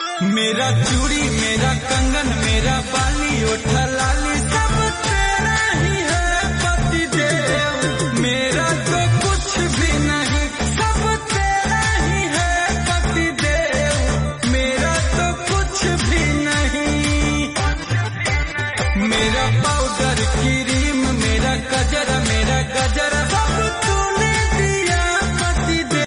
Bhojpuri Songs
• Simple and Lofi sound
• Crisp and clear sound